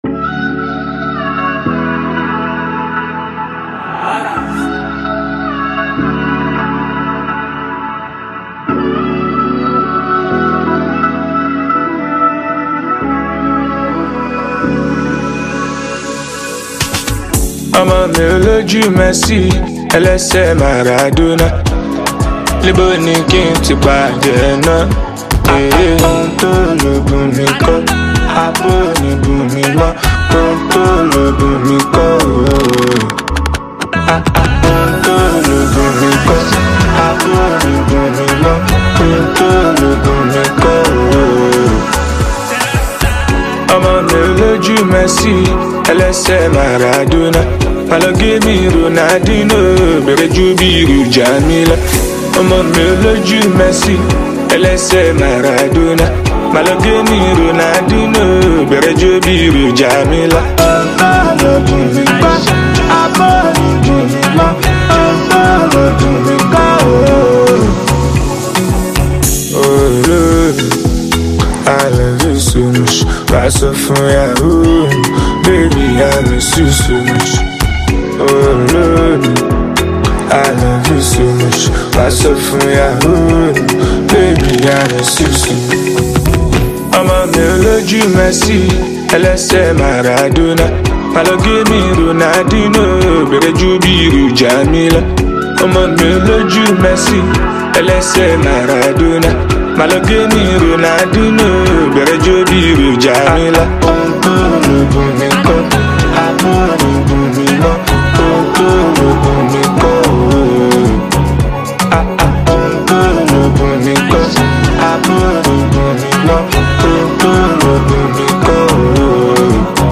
Nigeria talented Afrobeats singer and songwriter